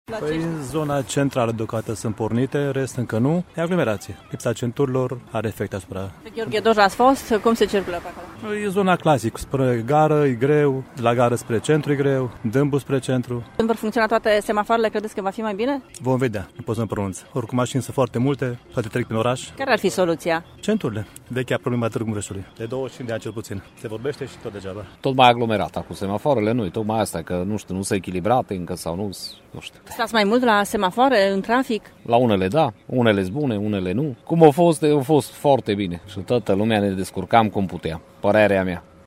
Șoferii nu cred că semafoarele noi vor rezolva problema traficului din Târgu Mureș, în condițiile în care nu există centuri ocolitoare: